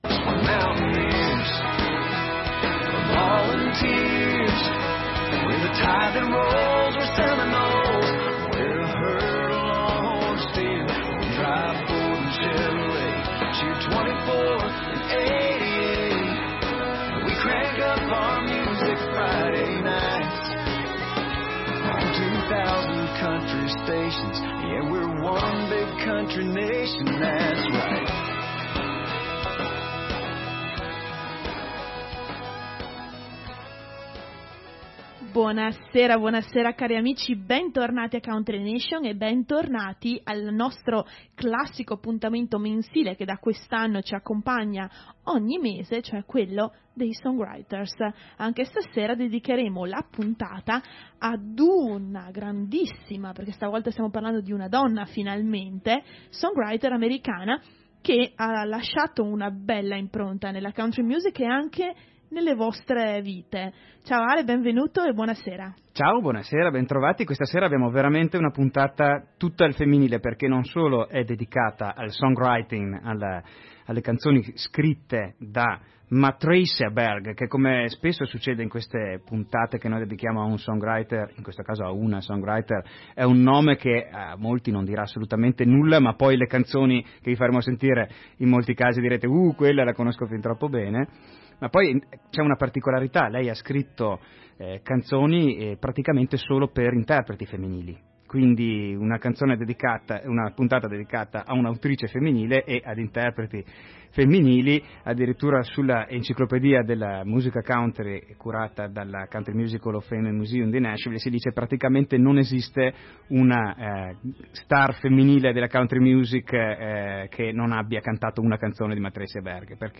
Una puntata, quindi, fatta di canzoni (famose) cantate ognuna da un artista diverso (famoso), ma accomunate dal fatto di portare tutte la firma dello stesso compositore (che probabilmente non avevate mai sentito nominare; eppure…).
Riprendiamo questa settimana con una puntata dedicata a un songwriter che quest’anno ha festeggiato i 30 anni di carriera a Nashville: un viaggio nel meglio del meglio della musica country dagli anni 90 ad oggi.